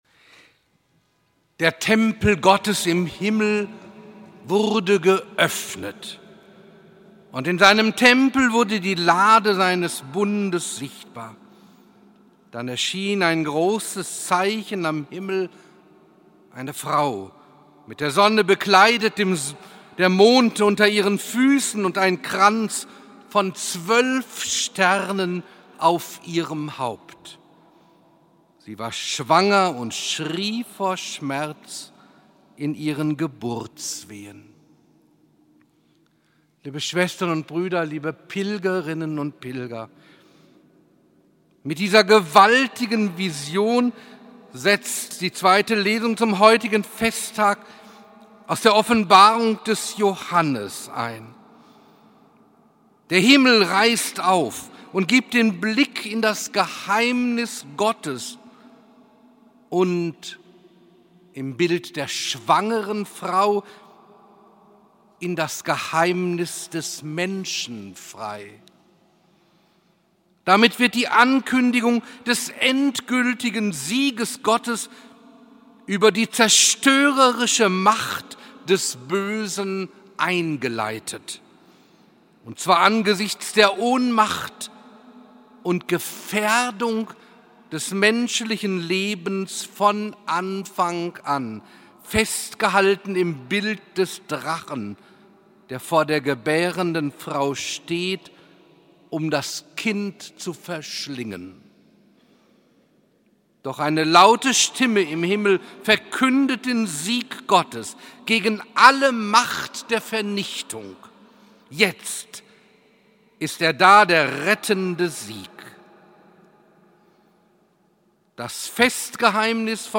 Bischof Dr. Karl-Heinz Wiesemann predigt am Patronatsfest Mariä Himmelfahrt.
Bischof Dr. Karl-Heinz Wiesemann predigt am Patronatsfest Mariä Himmelfahrt im voll besetzten Speyerer Dom Speyer.